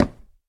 wood4.ogg